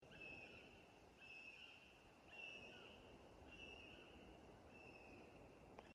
Birds -> Birds of prey ->
Common Buzzard, Buteo buteo
StatusVoice, calls heard